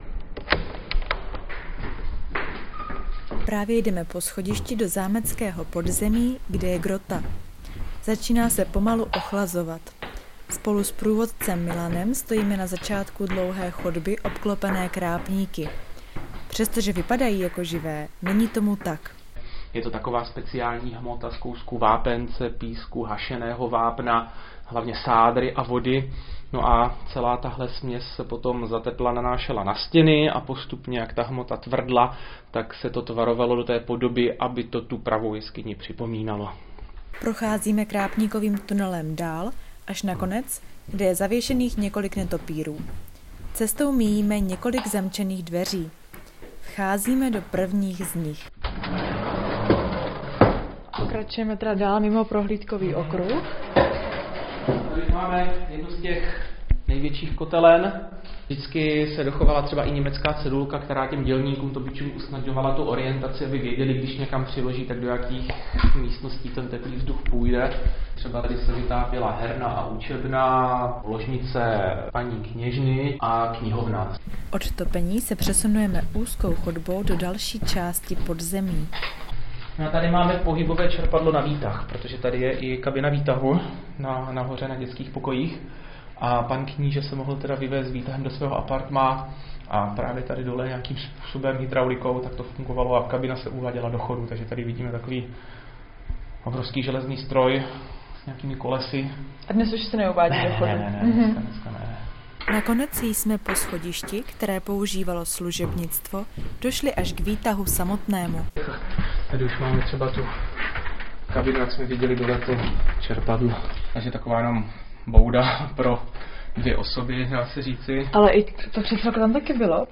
Reportáž: Co ukrývá podzemí jihomoravského zámku Lednice?